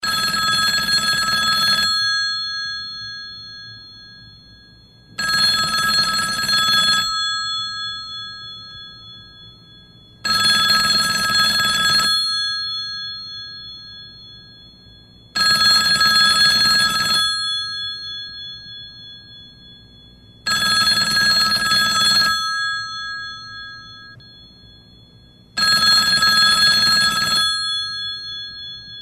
Kategorie Efekty Dźwiękowe